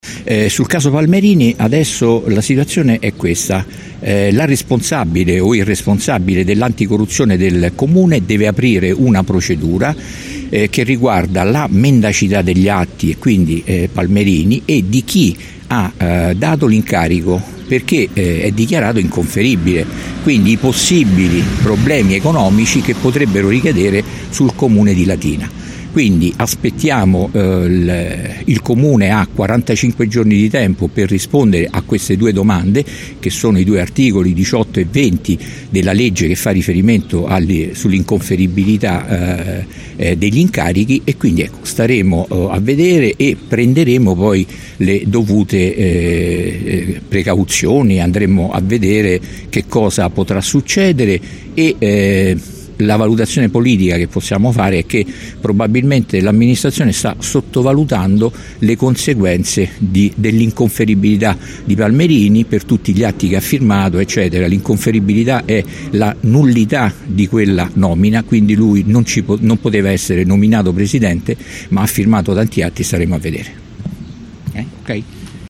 In una conferenza stampa che si è tenuta questa mattina nella sede di via Cattaneo, la capogruppo del movimento 5 stelle Maria Grazia Ciolfi,  quella del Pd Valeria Campagna, il capogruppo di Lbc Dario Bellini e il capogruppo di Latina per 2032 Nazareno Ranaldi hanno affrontato la questione che ha portato da due anni a questa parte a puntuali scontri in consiglio comunale.